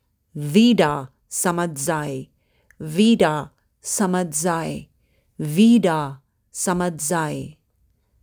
(Avec prononciation audio)